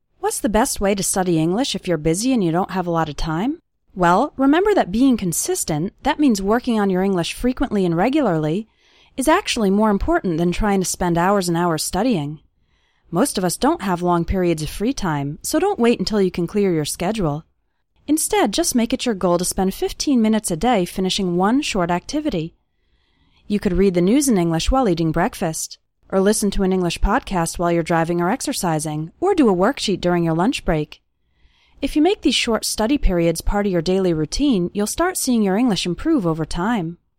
Here are three activities you can do with this sample of spoken English: